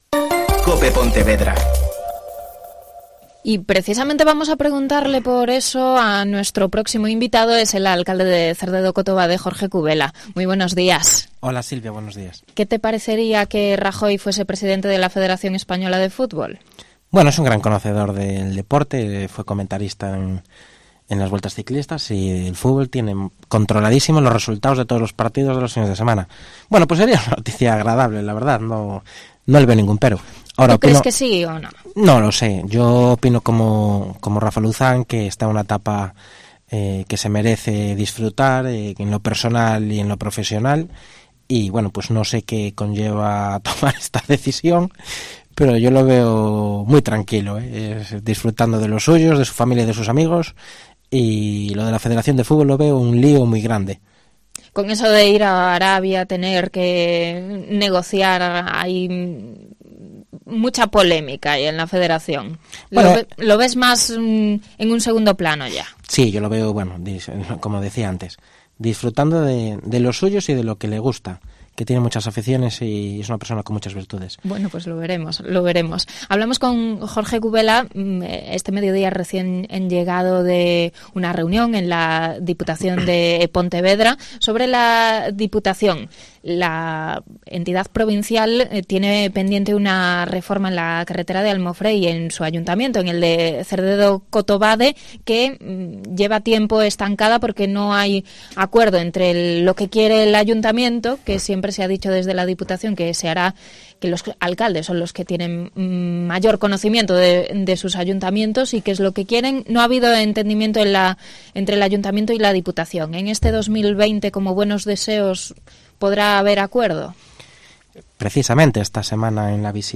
Entrevista a Jorge Cubela, alcalde de Cerdedo-Cotobade